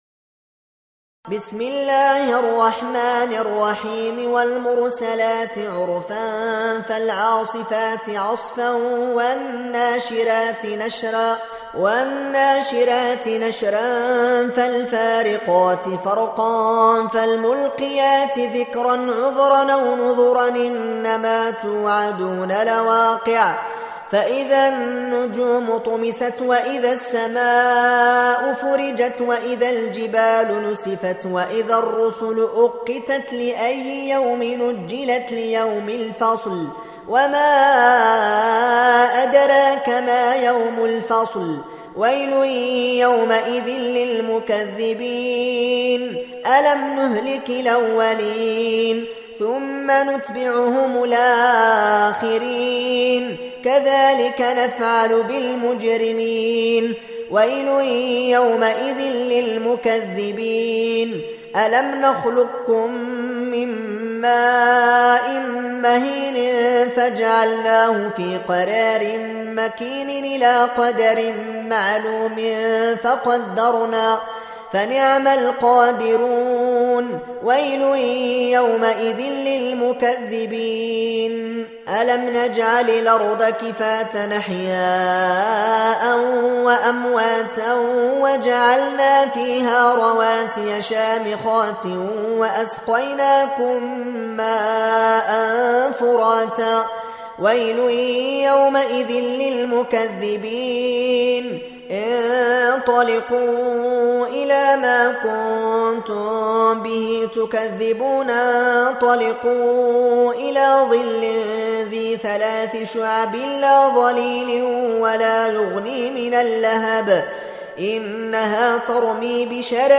77. Surah Al-Mursal�t سورة المرسلات Audio Quran Tarteel Recitation
حفص عن عاصم Hafs for Assem